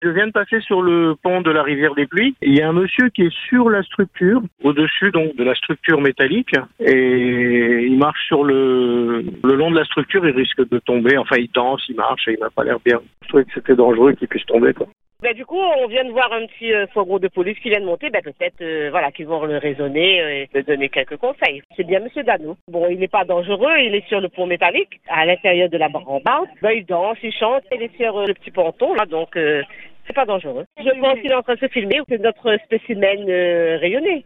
Très vite, l’inquiétude monte sur l’antenne. Mais peu après, une auditrice intervient et apporte un éclairage inattendu : elle affirme reconnaître l’individu.